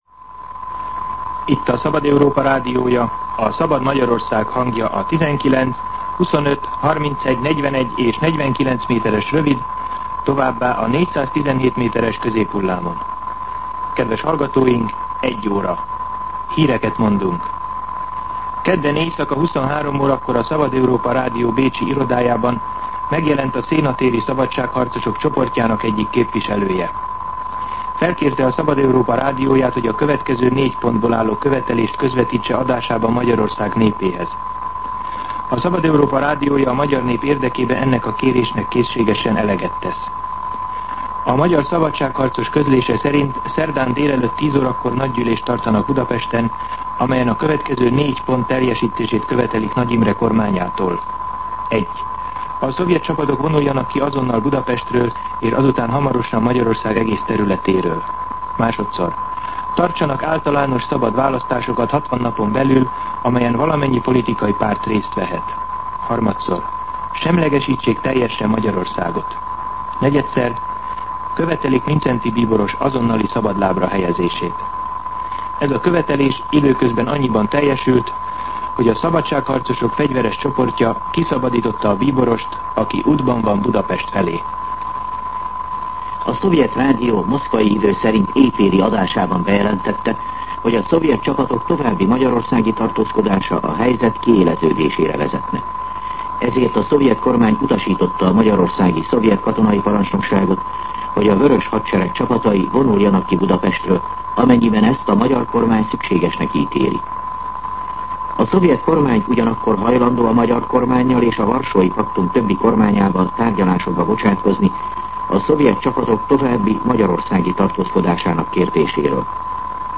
MűsorkategóriaHírszolgálat